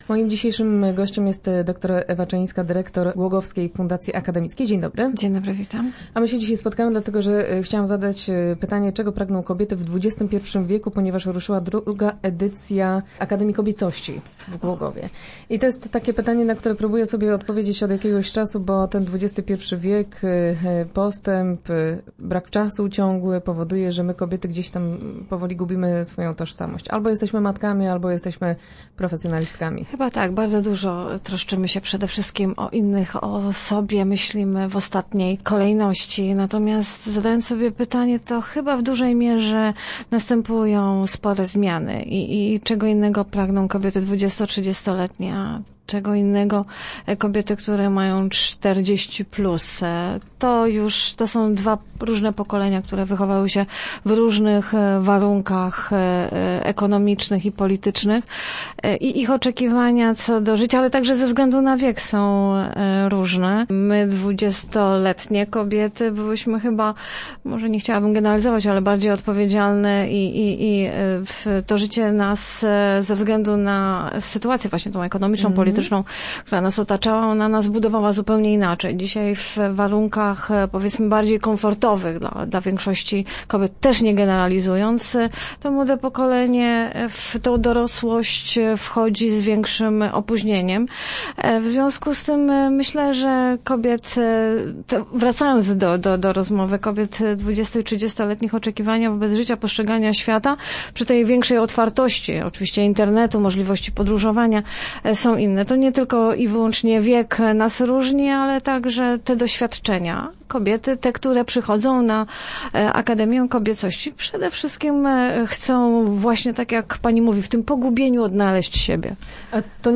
Start arrow Rozmowy Elki arrow Czego pragną kobiety?